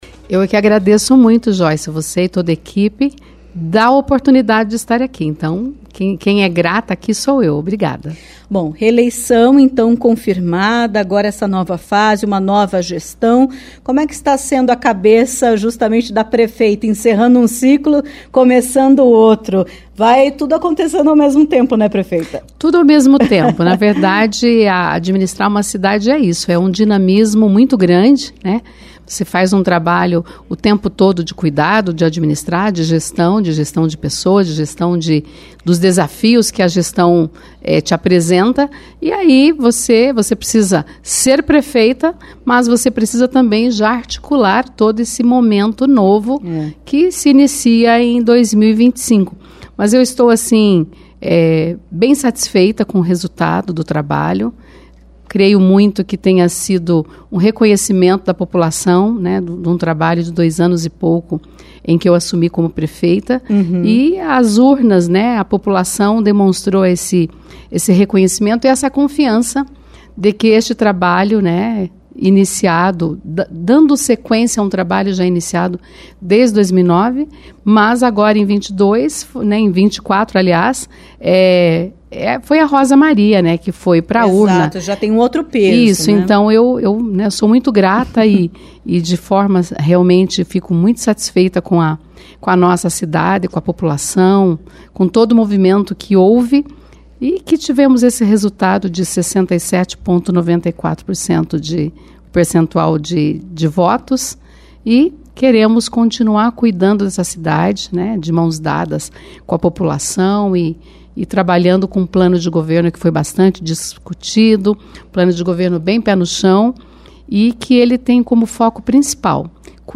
A prefeita reeleita de Pinhais, Rosa Maria (PSD), esteve na CBN Curitiba nesta terça-feira (26) e destacou este período entre o encerramento do primeiro mandato e o início do próximo, em 2025.